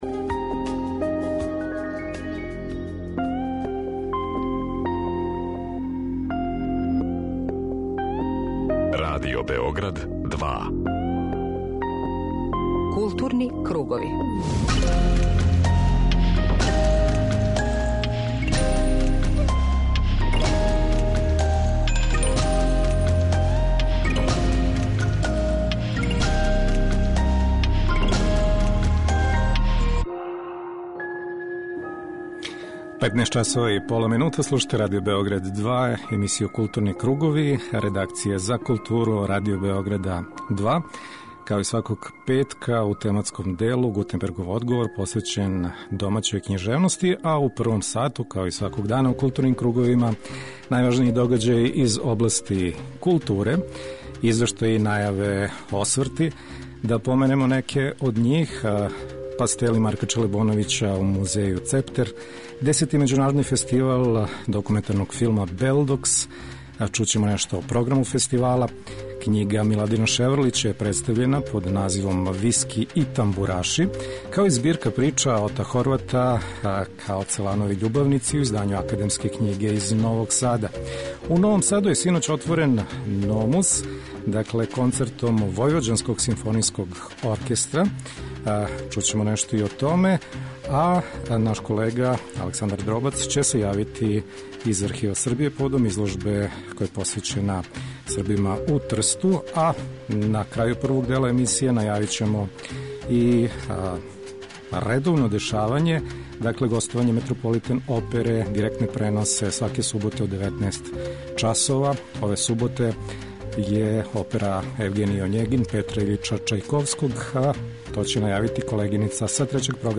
преузми : 41.16 MB Културни кругови Autor: Група аутора Централна културно-уметничка емисија Радио Београда 2.